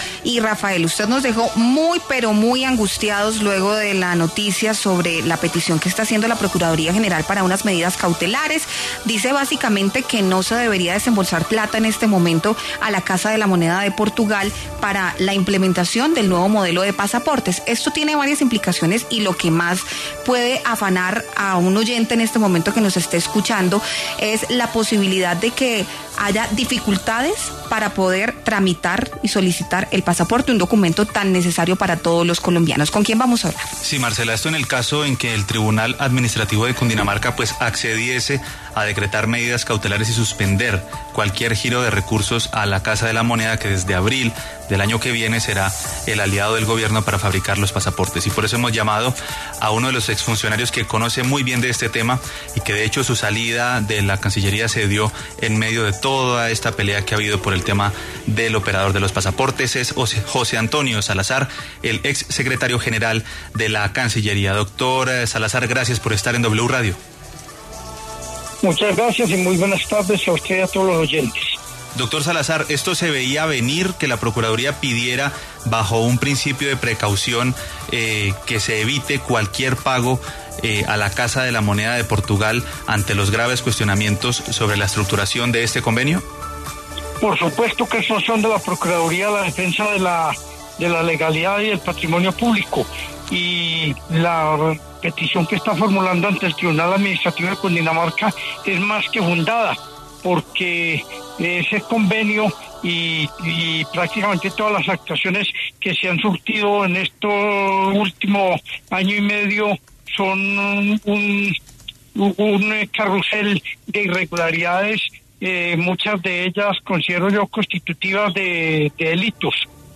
En entrevista con La W, el exsecretario general de la Cancillería, José Antonio Salazar, respaldó la petición de la Procuraduría al Tribunal Administrativo de Cundinamarca para que suspenda, vía medidas cautelares, cualquier giro de recursos a la Casa de la Moneda de Portugal, en el marco del demandado convenio para la fabricación de pasaportes.